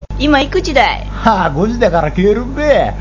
医療人のための群馬弁講座；慣用句